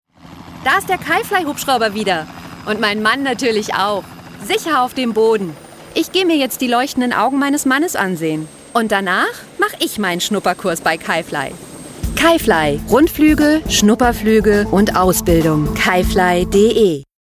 warme Stimmfarbe, Stimmalter 25-45, 25 Jahre Theatererfahrung
Kein Dialekt
Sprechprobe: Werbung (Muttersprache):